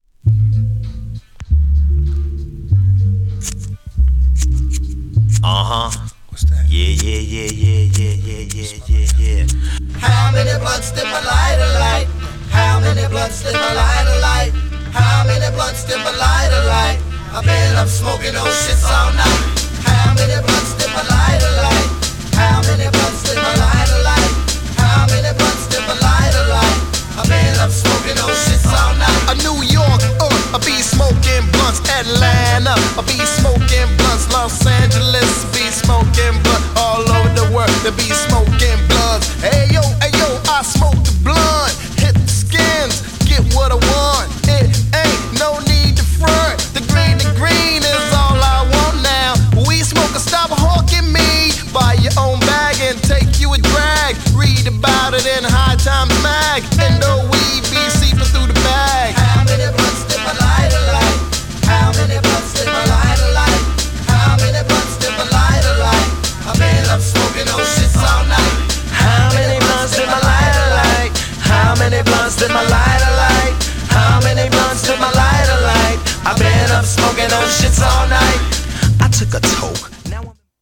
GENRE Hip Hop
BPM 111〜115BPM